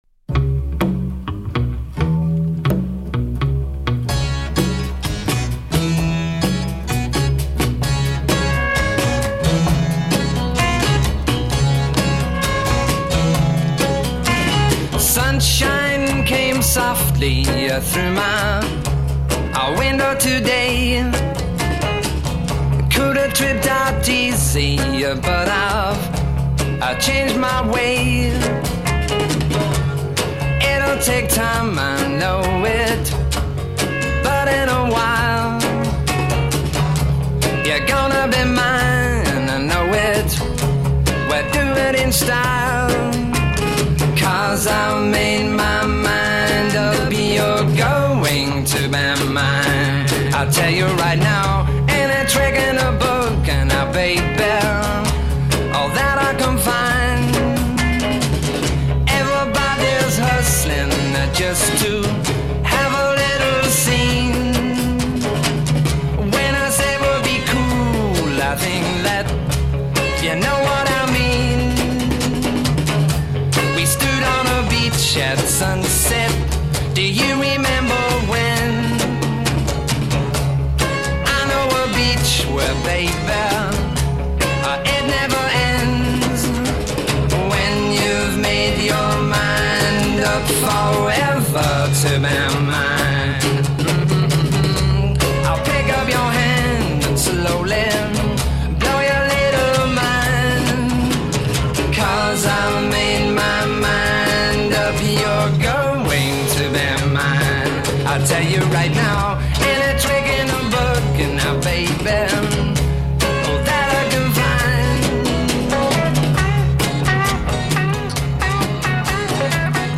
guitar and vocal
guitars
bass
drums
percussion
harpsichord
Intro   Electric and acoustic bass with conga drum intro,
joined by harpsichord, drums and electric guitar.
Verse   Solo voice over ensemble (drop harpsichord). a
Refrain   Double-tracked harmony. b
Verse   Guitar solo (drop harpsichord) *
Refrain   Repeat and fade *